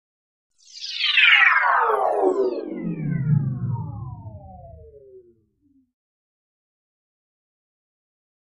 Sweep High Frequency Electronic Flutter Sweep with Shimmer, Descend